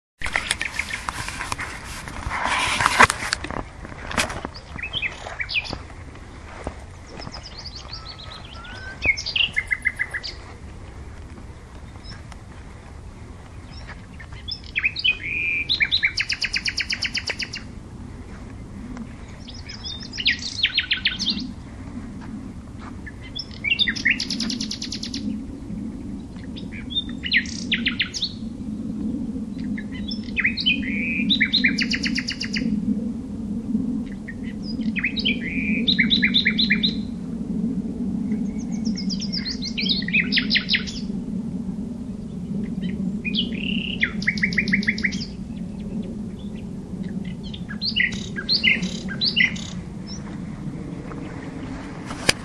Geluid Nachtegaal 2